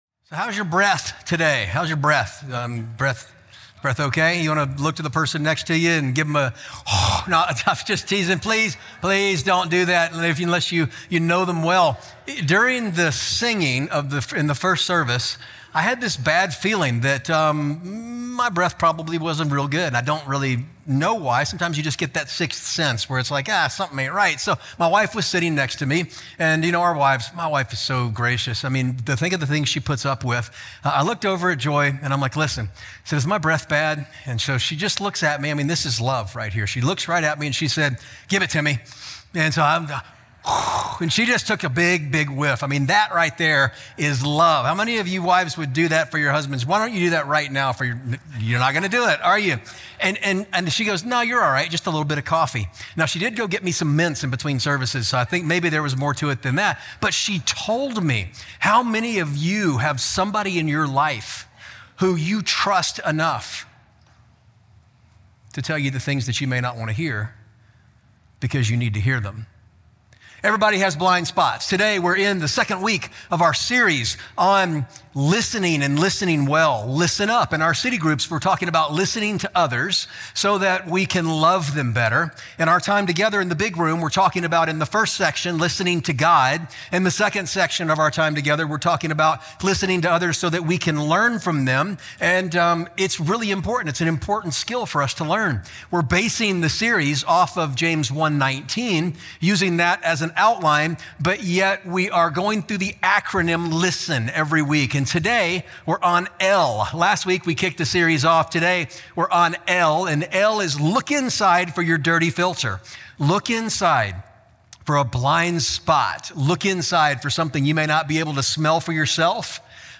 Capitol City Church Podcast (Sermon Audio) (Sermon Audio)